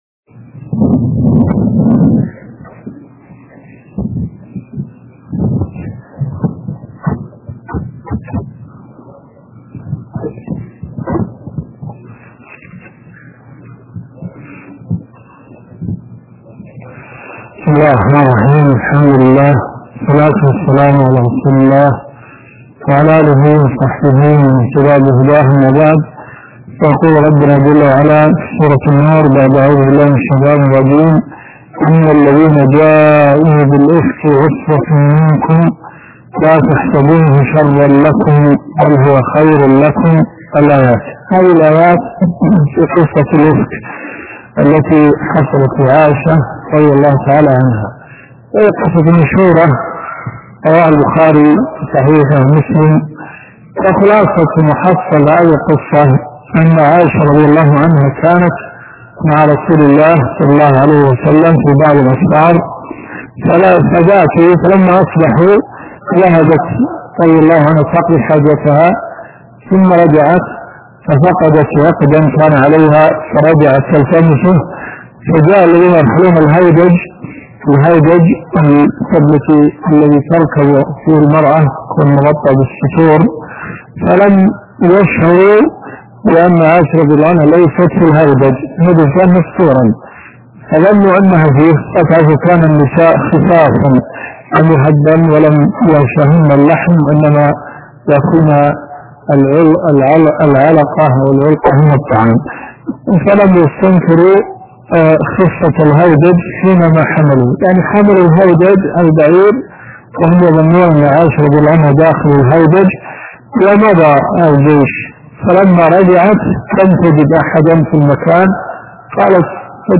الرئيسية الدورات الشرعية [ قسم التفسير ] > تفسير سورة النور . 1434 .